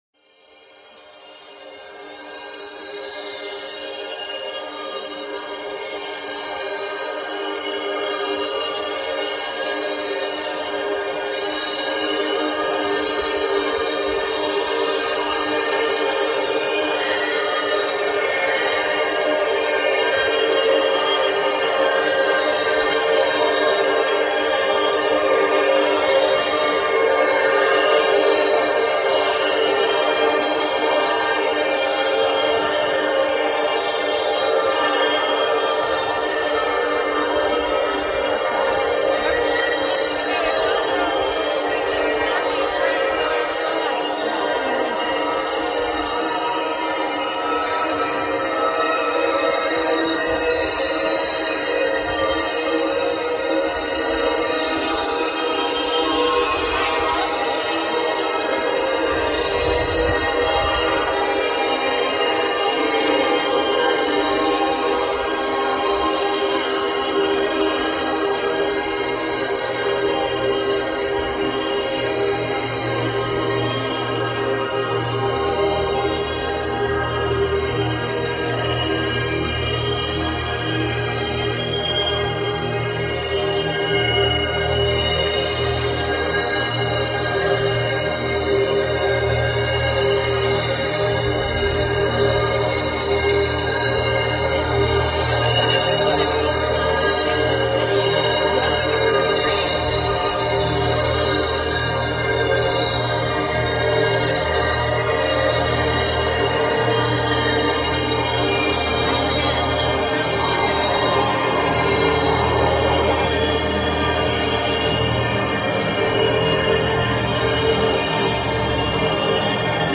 Streamed Live from Art Omi.